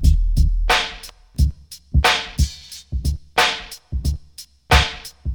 89 Bpm Drum Loop Sample D# Key.wav
Free breakbeat sample - kick tuned to the D# note. Loudest frequency: 1616Hz
89-bpm-drum-loop-sample-d-sharp-key-coe.ogg